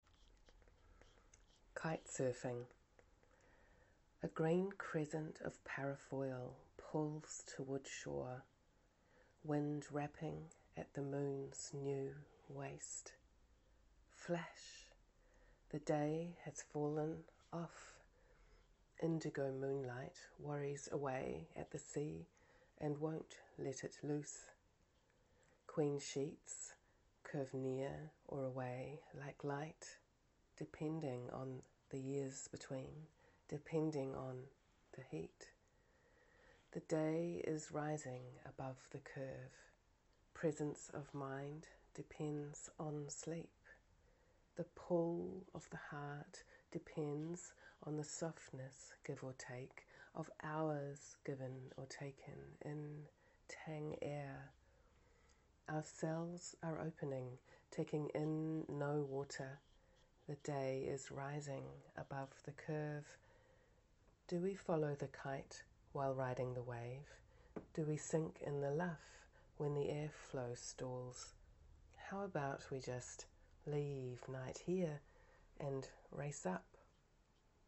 kitesurfing-16-4-19-ne.mp3